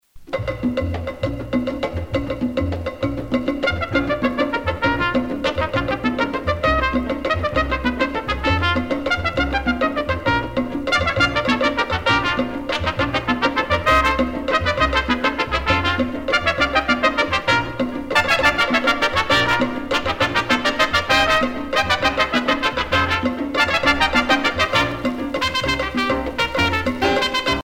Usage d'après l'analyste gestuel : danse ;
Pièce musicale éditée